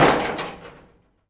metal_sheet_impacts_03.wav